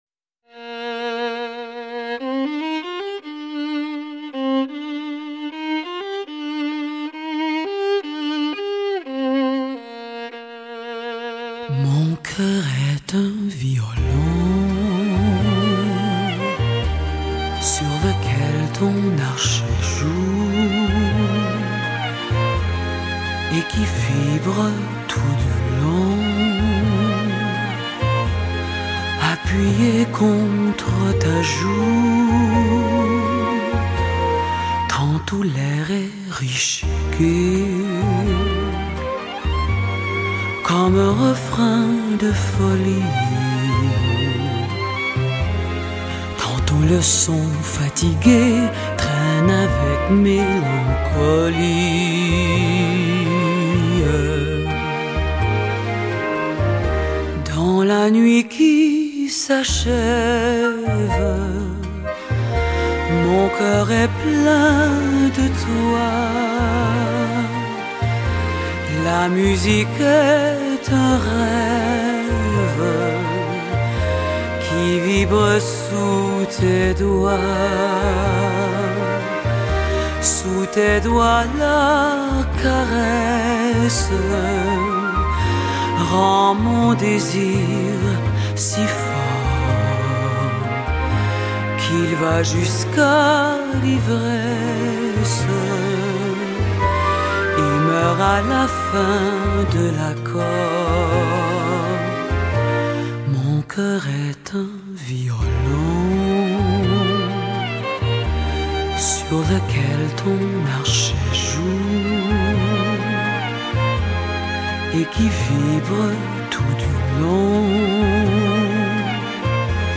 动人的旋律为此曲带来一般的成功，而另一半的成功，则来自于小提琴让人沉醉痴迷的编曲安排